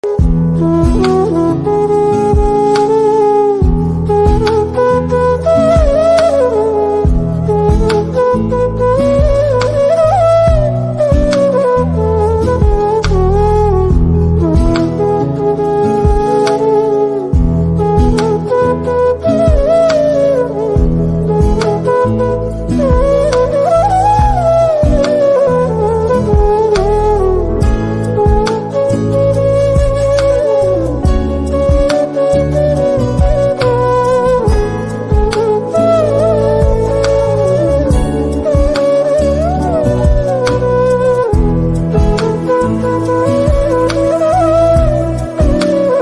Heart Touching Flute Ringtone